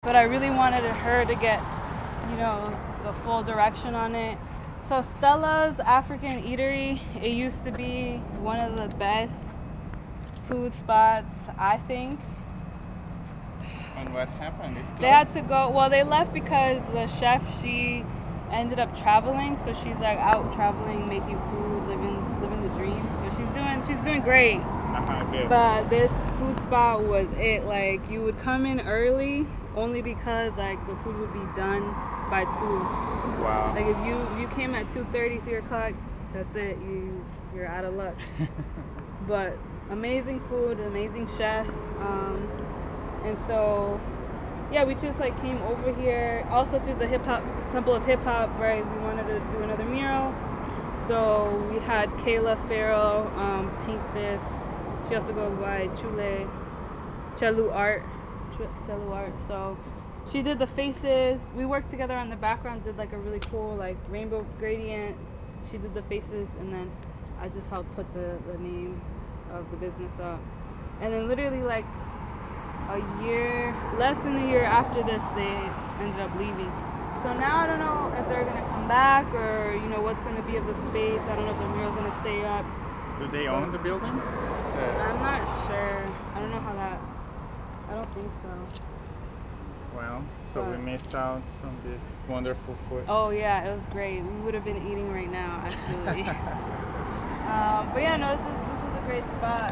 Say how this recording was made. Stella’s African Eatery · Frog Hollow Oral History